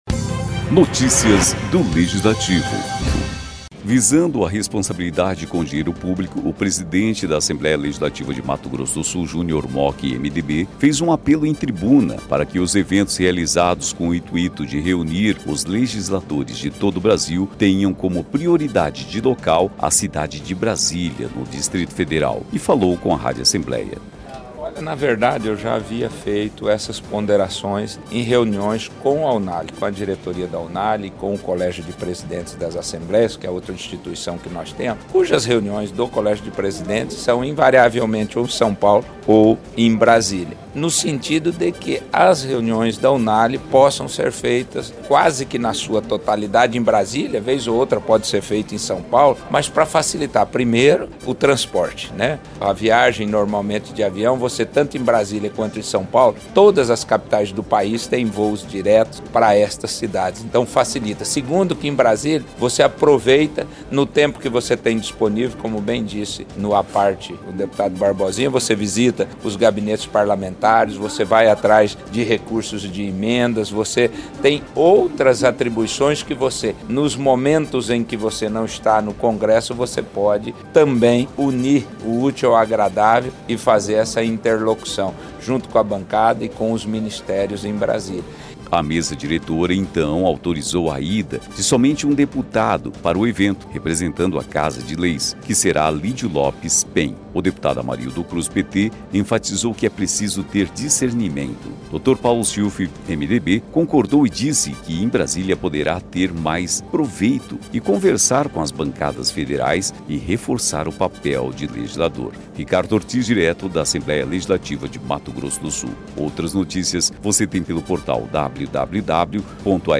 Visando a responsabilidade com o dinheiro público, o presidente da Assembleia Legislativa de Mato Grosso do Sul, Junior Mochi (PMDB), fez um apelo em tribuna para que os eventos realizados com o intuito de reunir os legisladores de todo o Brasil tenham como prioridade de local a cidade de Brasília (DF). Em discurso na sessão ordinária desta quinta-feira (26), ele criticou o próximo encontro da União Nacional dos Legisladores e Legislativos Estaduais (Unale), que será realizado em Gramado (RS), de 8 a 11 de maio.